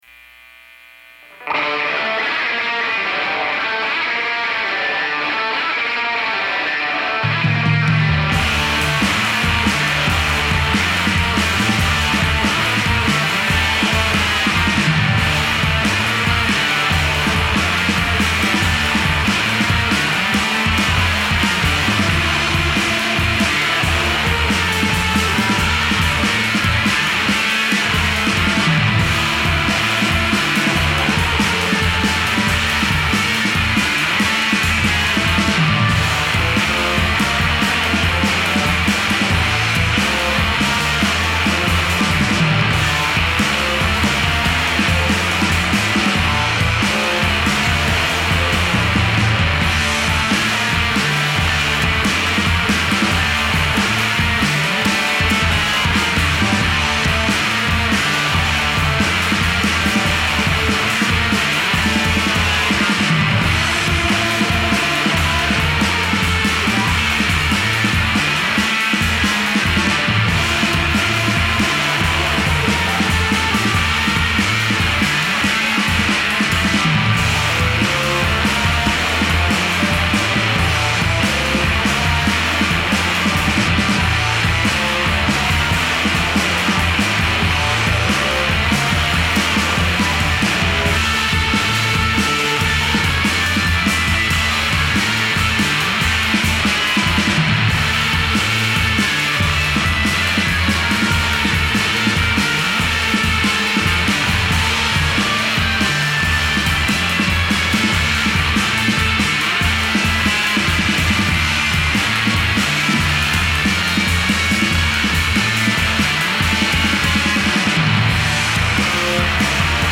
fiery blurs of guitar